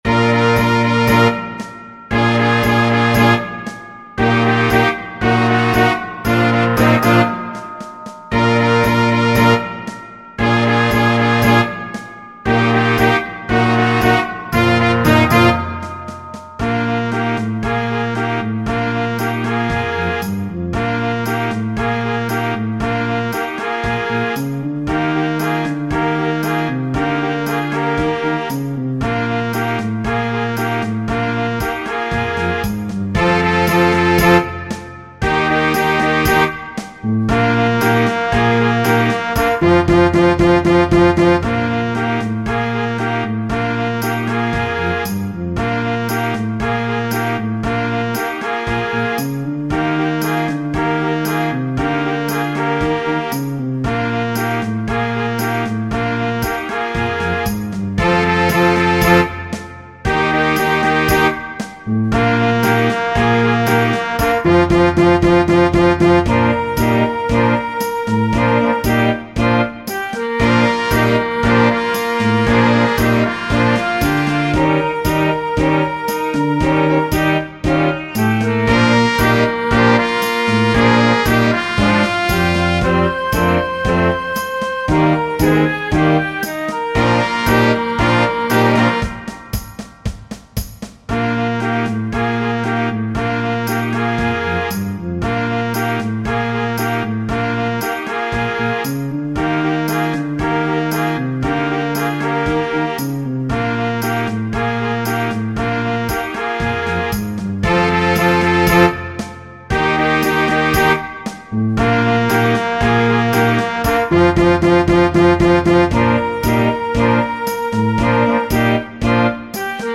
Destiné à un jeune orchestre